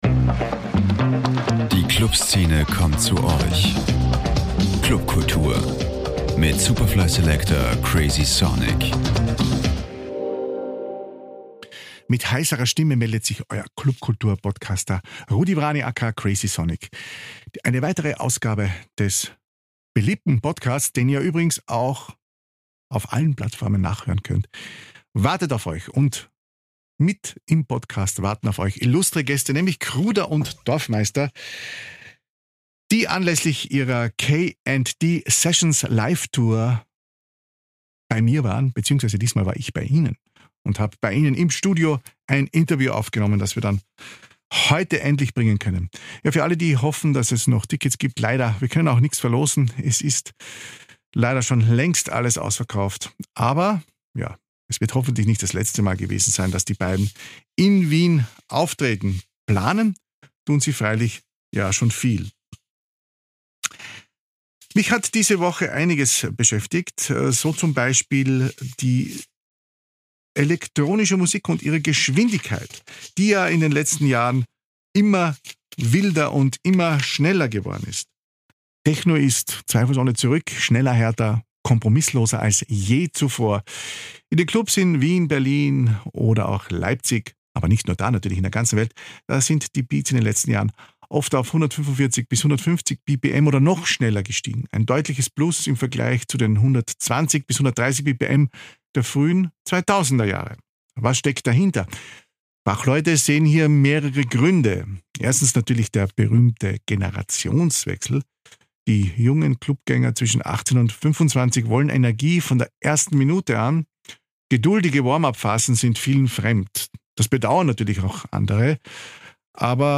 Interviews mit Peter Kruder und Richard Dorfmeister